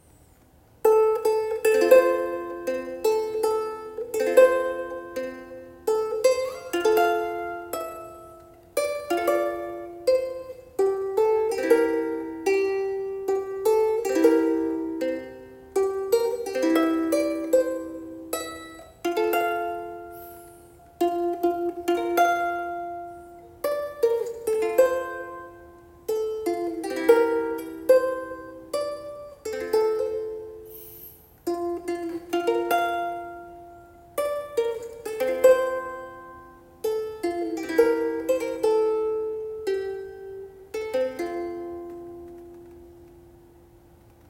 日本の木シリーズ　コンサートウクレレ　№92　 (オニグルミ・ケヤキ・ヤマザクラなど)
ぽろりんと鳴らしてみて、「お、いい感じ！」という音色になりました。
音響的にもホッと安心できるような落ち着いた音色の中に明るさもあり、楽器材として魅力十分です。